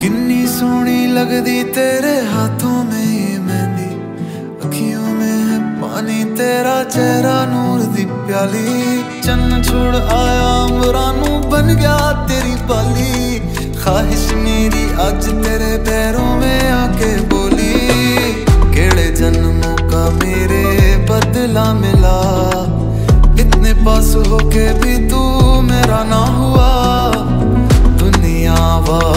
Hindi song